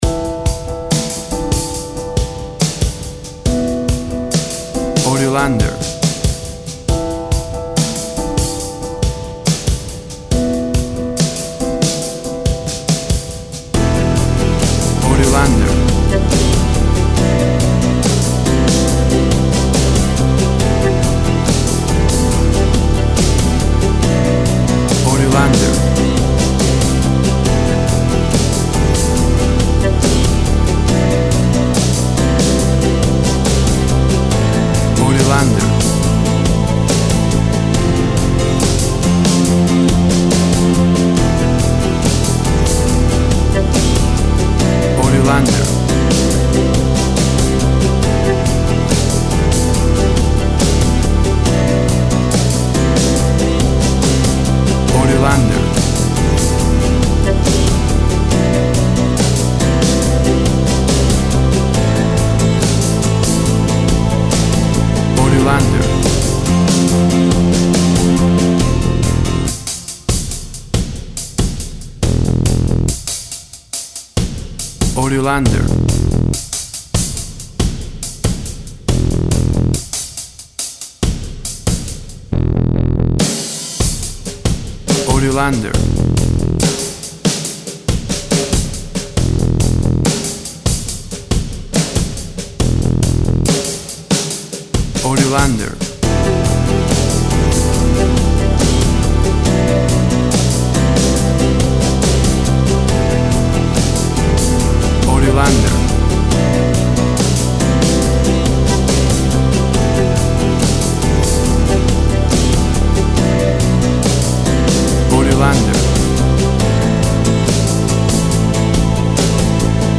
Drama and nostalgic ambient rock sounds.
Tempo (BPM) 70